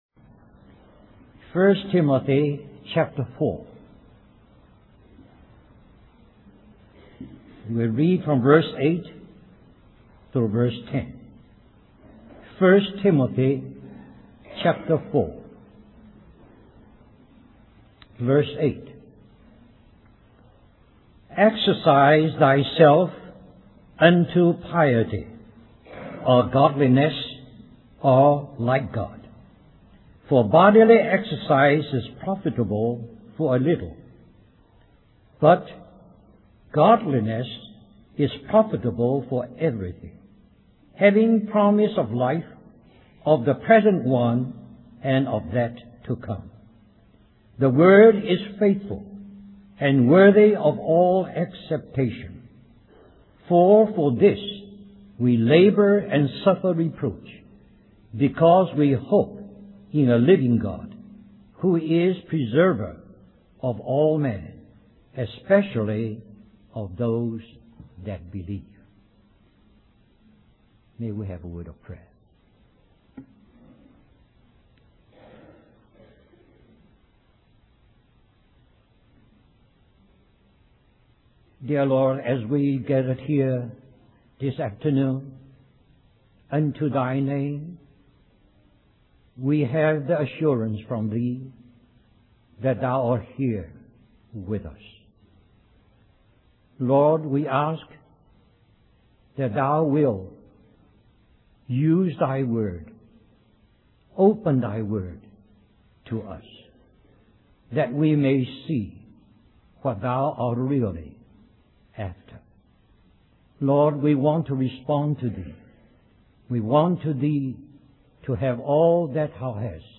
1998 Christian Family Conference Stream or download mp3 Summary This message is also printed in booklet form under the title